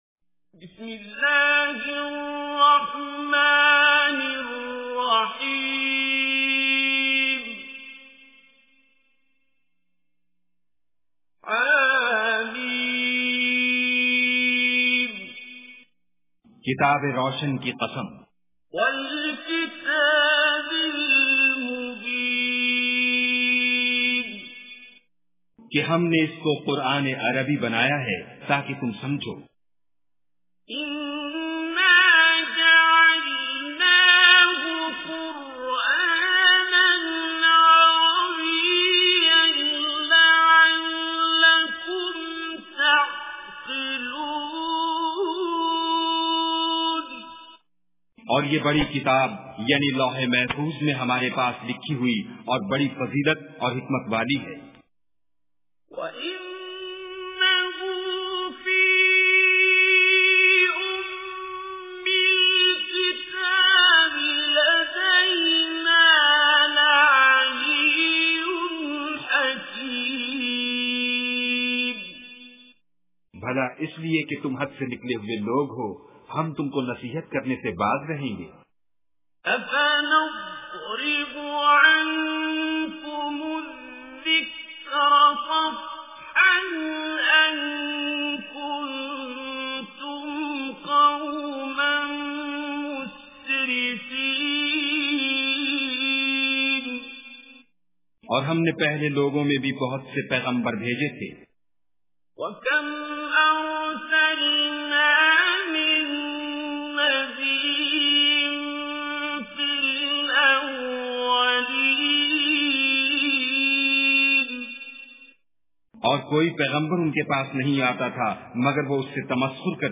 Surah Az Zukhruf Recitation with Urdu Translation
Surah Az Zukhruf is 43rd chapter of Holy Quran. Listen online and download mp3 tilawat / recitation of Surah Az Zukhruf in the beautiful voice of Qari Abdul Basit As Samad.